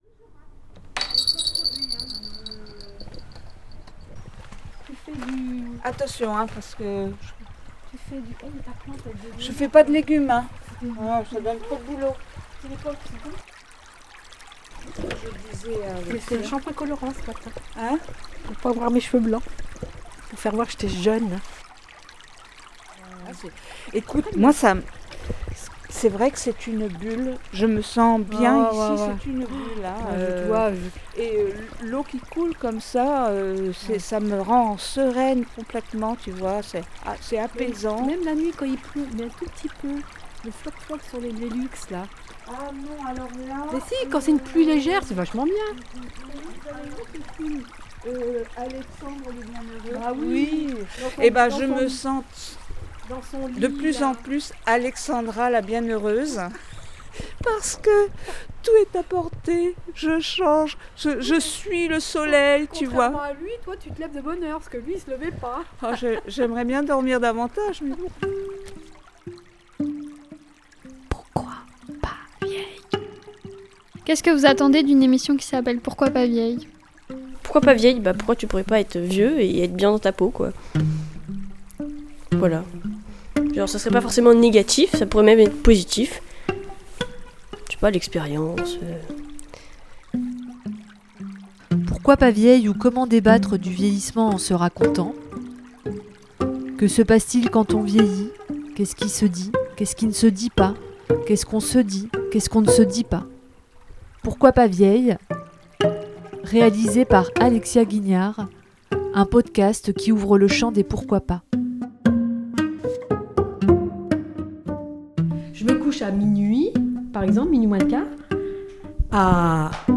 Pourquoi pas vieilles à trois voix.